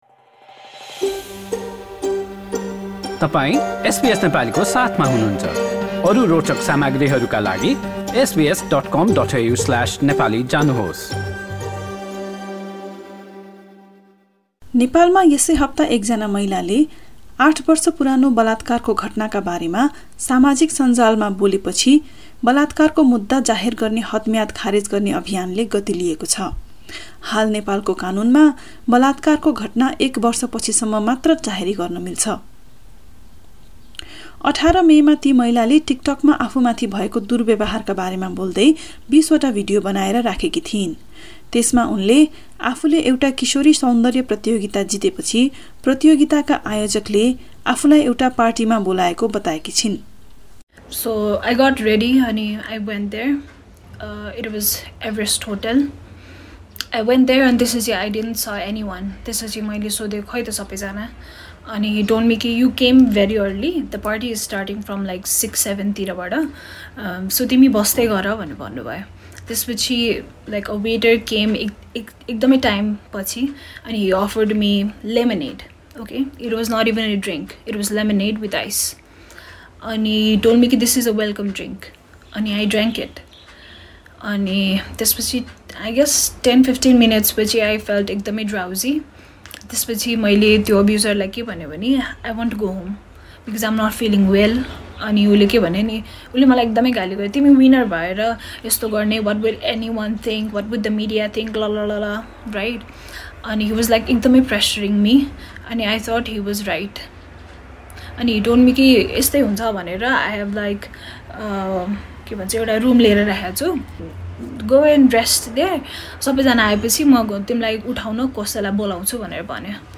सामाजिक सञ्जालमा देखिएका भनाइ सहितको रिपोर्ट यहाँ सुन्नुहोस्: null हाम्रा थप अडियो प्रस्तुतिहरू पोडकास्टका रूपमा उपलब्ध छन्।